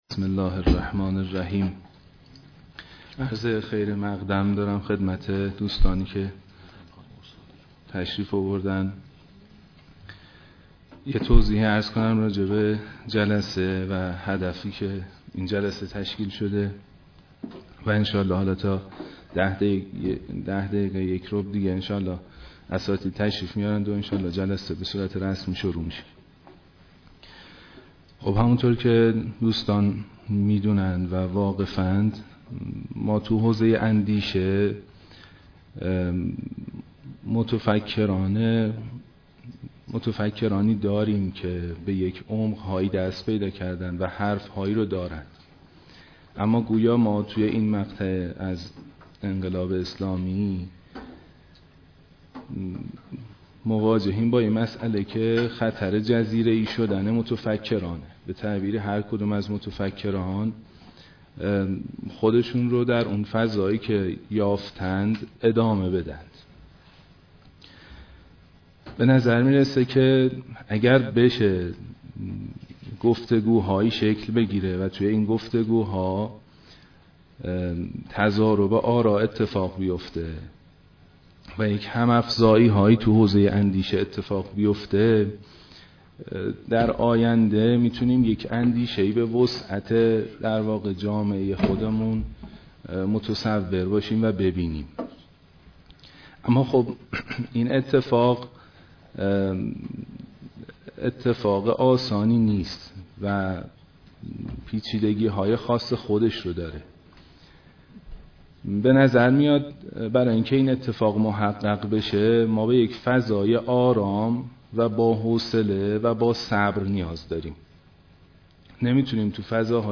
در سالن کنفرانس خانه بیداری اسلامی